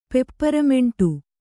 ♪ pepparameṇṭu